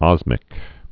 (ŏzmĭk)